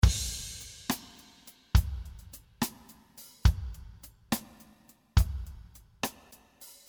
This 105 bpm drum loop belong to the 6/8 family.
You can all so use as a slow rock style by putting the loops in a sequencer in 70 bpm using 4/4 signature.
This drum loop contains 35 variations.